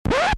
Scratch Speed
cue error fail mistake oops record request scratch sound effect free sound royalty free Memes